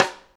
high rim shot p.wav